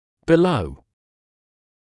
[bɪ’ləu][би’лоу]внизу, ниже; под